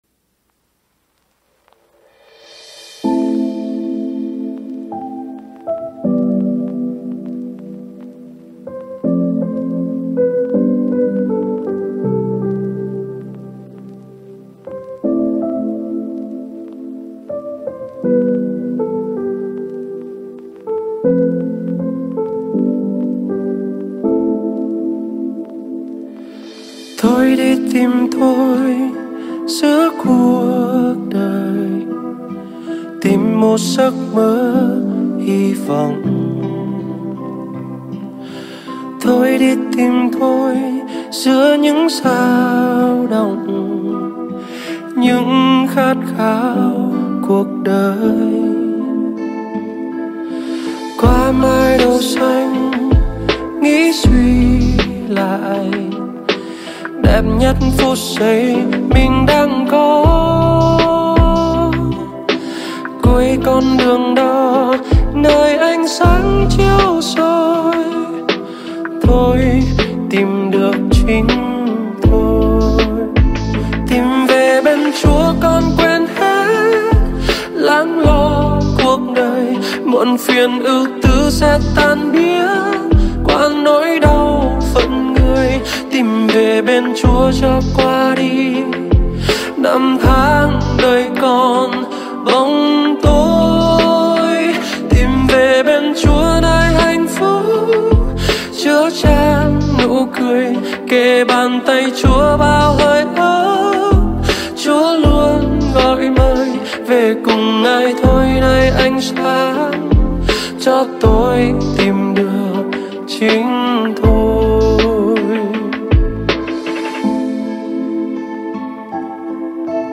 Thánh Ca Dâng Hiến